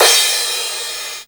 • Crash Cymbal Single Hit D# Key 27.wav
Royality free crash cymbal single shot tuned to the D# note. Loudest frequency: 5860Hz
crash-cymbal-single-hit-d-sharp-key-27-S9U.wav